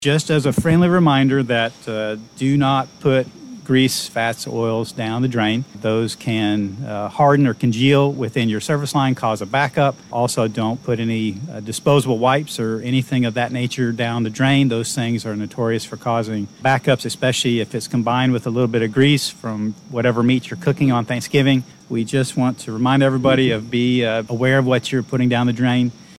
Appearing on CITY MATTERS on KWON Radio